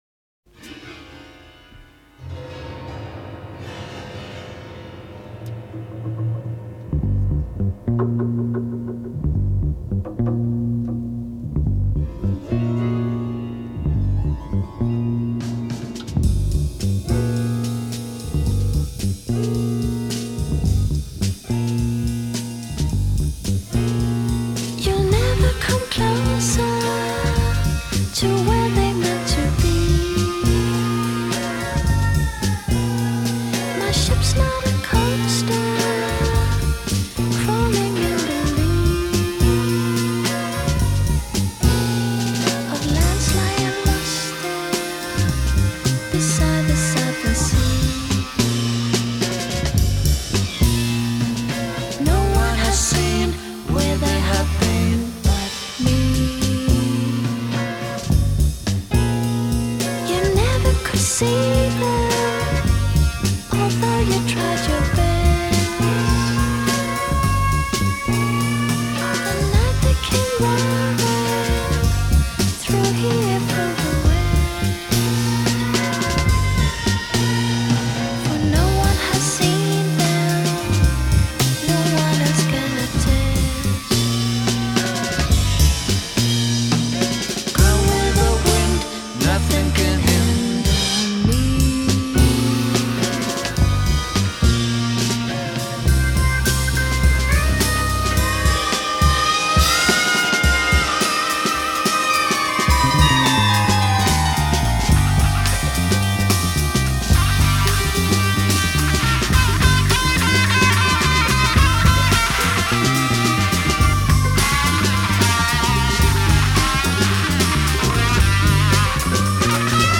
Answer: It’s all of the above!